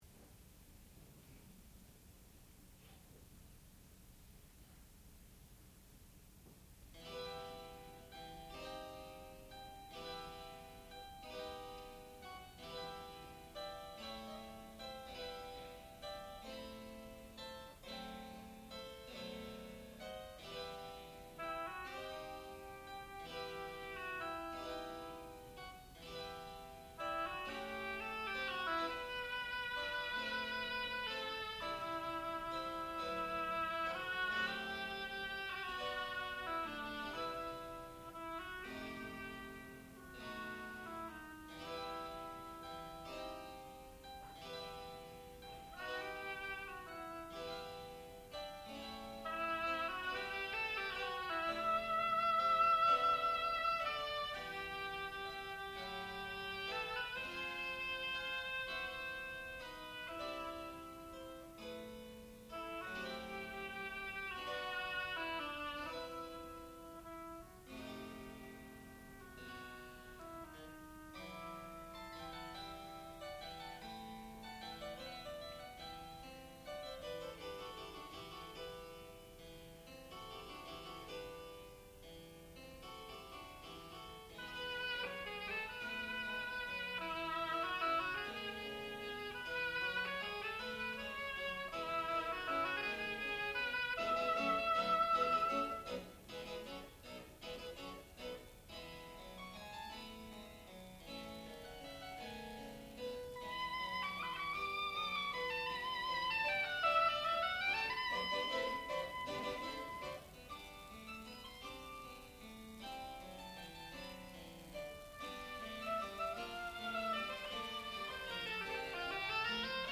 sound recording-musical
classical music
harpsichord and Evelyn Barbirolli